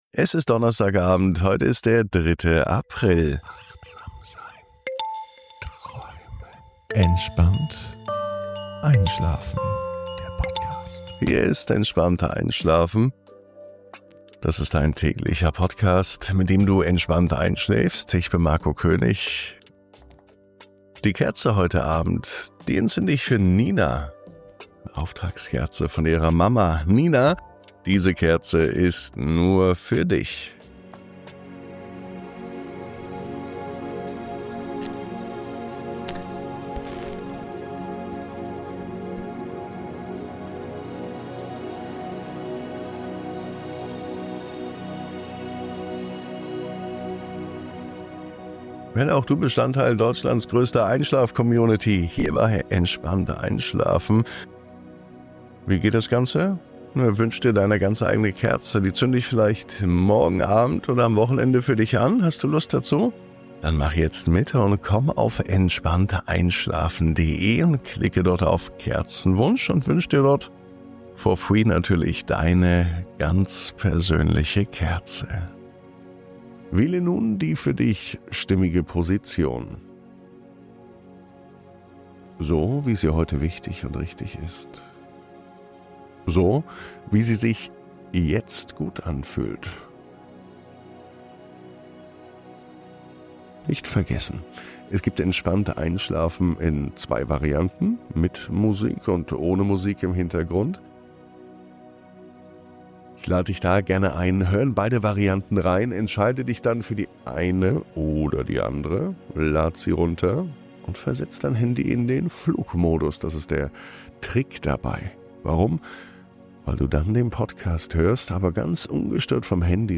Spüre die kühle Frische auf deiner Haut, atme den Duft von Blumen und Moos ein und lass dich zu einem glitzernden See führen – einem Ort der vollkommenen Stille und inneren Ruhe. Diese Folge schenkt dir eine tiefe Geborgenheit, begleitet von sanfter Anleitung und Achtsamkeit – perfekt für einen entspannten Start in die Nacht.